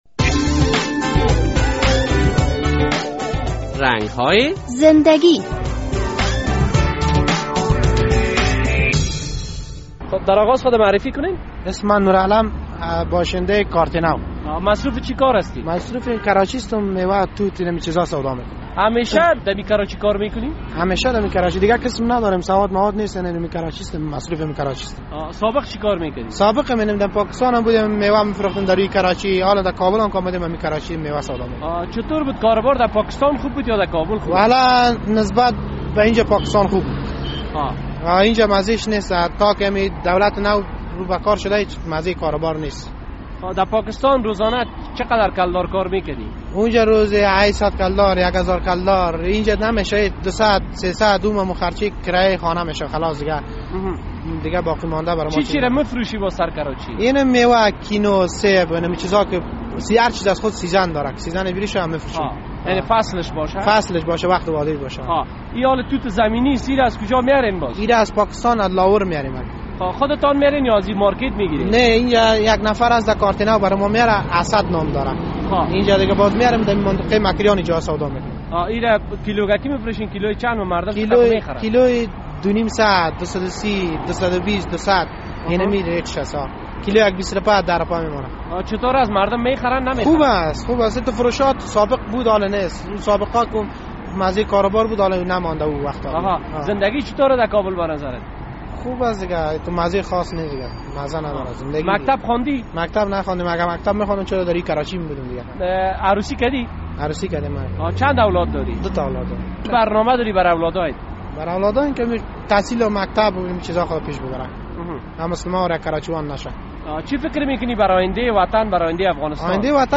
در این برنامه رنگ های زندگی با یک دست فروش مصاحبه شده است که در کنار سرک توت زمینی می فروشد و از این طریق یک لقمه نان حلال برای...